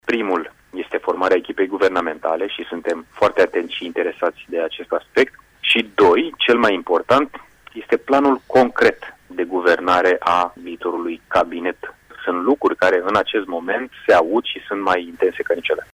Purtătorul de cuvânt liberal, Ionuţ Stroe, aminteşte care sunt paşii cei mai importanţi pe care premierul desemnat trebuie să-i facă în alcătuirea noului Guvern: